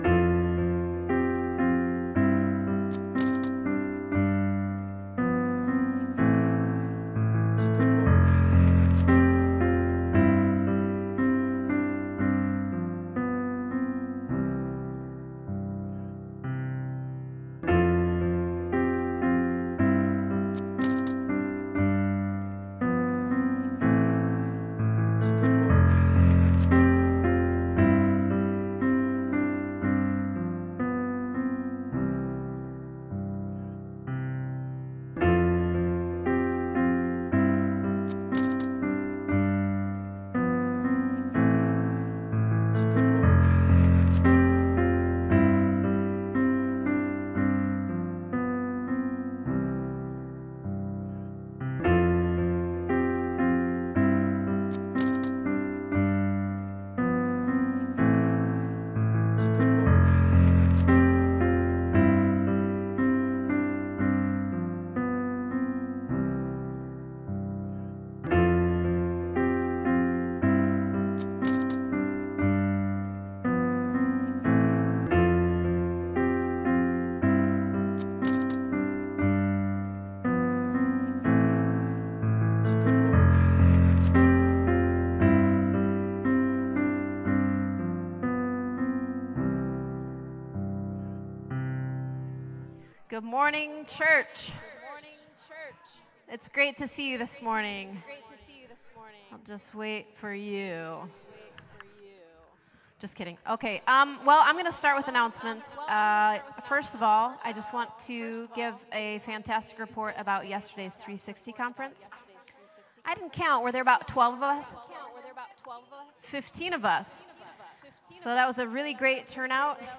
Faith-The-Church-Sermon-Audio-CD.mp3